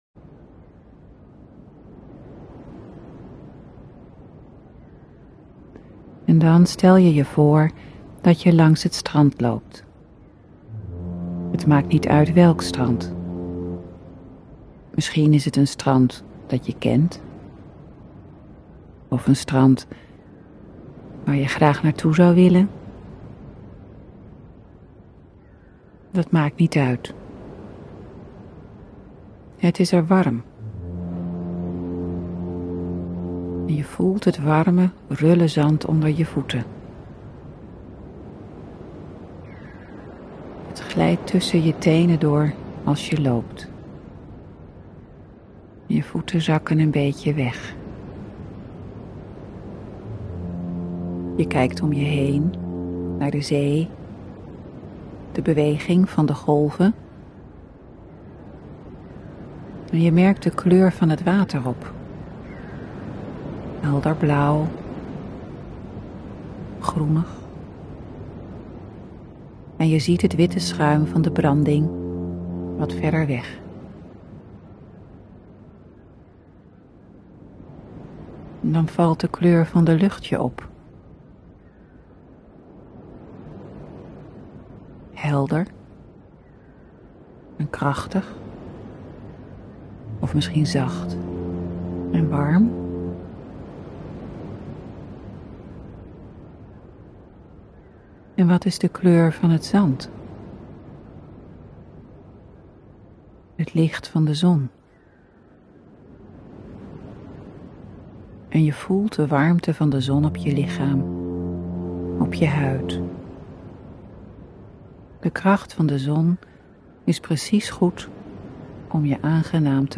Geluidsfragment beluisteren van de oefening Het strand.